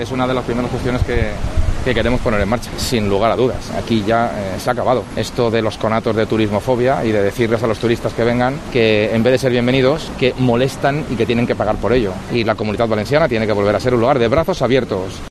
Así se ha expresado este jueves Mazón en Alicante, al ser preguntado por los medios de comunicación por la derogación de esta tasa, algo que prometió en campaña electoral.